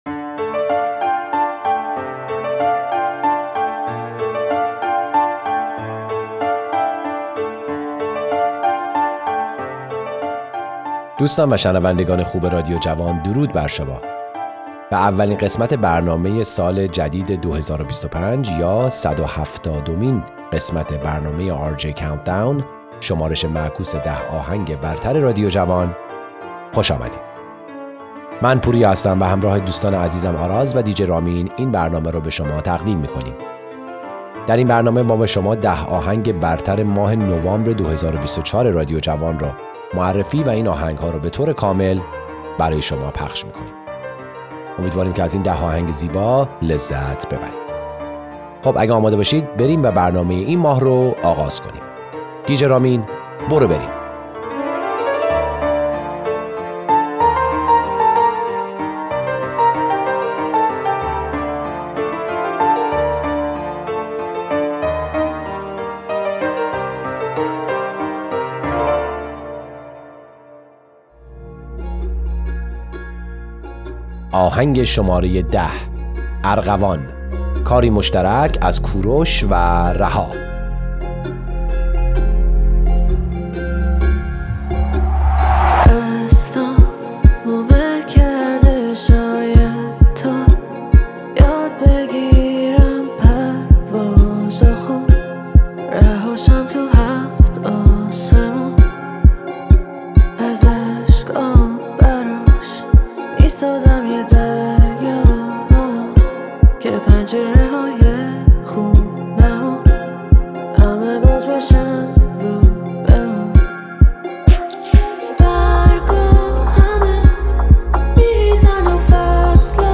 دانلود ریمیکس جدید